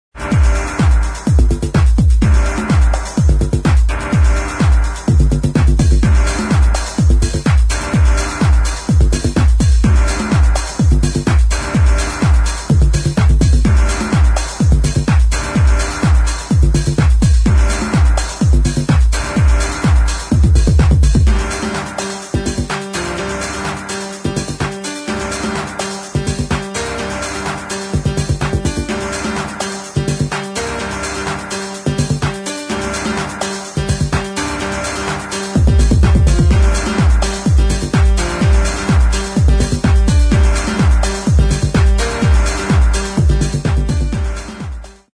[ DEEP HOUSE ]
シカゴ～デトロイトを通過したダブ・ハウス・サウンド！！